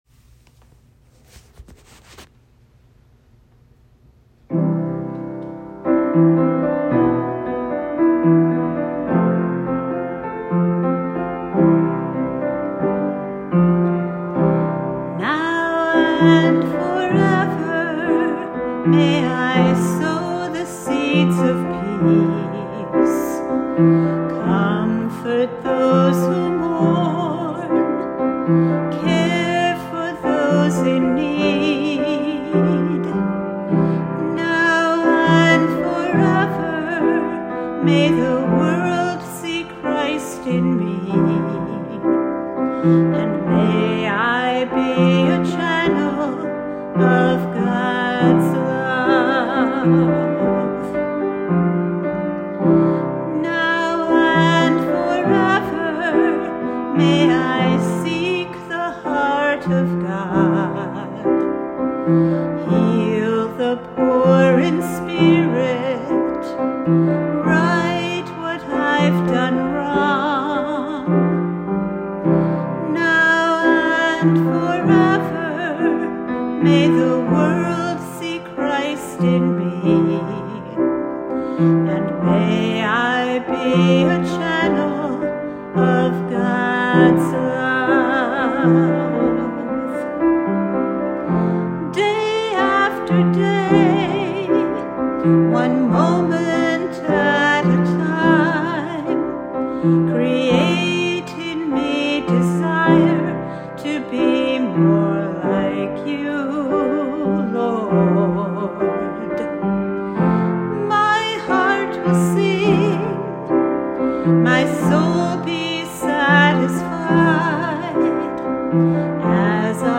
meditative song